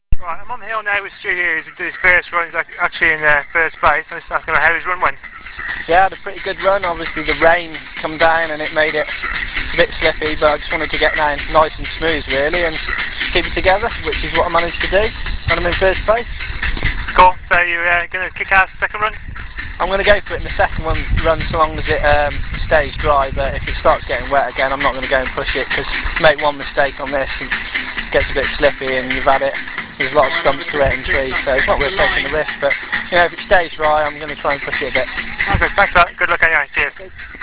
Interviews.
We have two interviews just after their first runs of the morning.